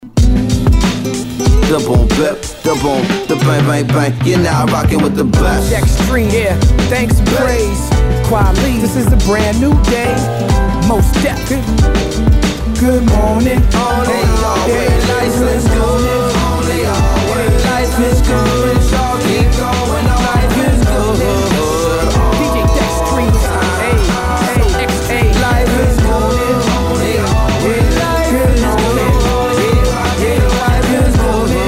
Tag       HIP HOP HIP HOP